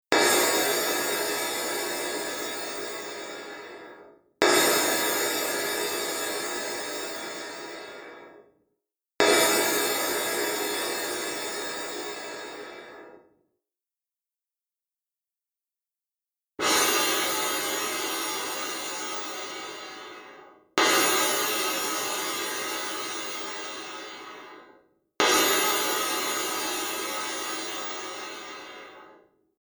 Here's a recording of my patch, using crash-cymbal settings:
crash cymbals example (MP3)
sc_cymbals_1.mp3